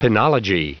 Prononciation du mot penology en anglais (fichier audio)
Prononciation du mot : penology